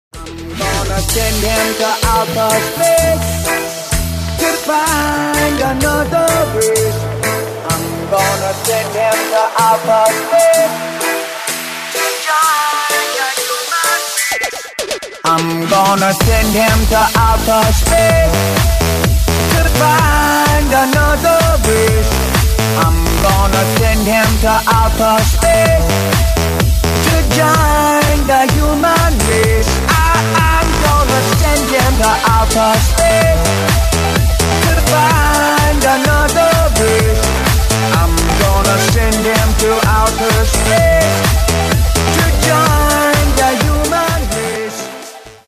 танцевальные рингтоны